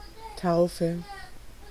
Ääntäminen
Synonyymit christening Ääntäminen US Tuntematon aksentti: IPA : /ˈbæptɪzəm/ Haettu sana löytyi näillä lähdekielillä: englanti Käännös Konteksti Ääninäyte Substantiivit 1.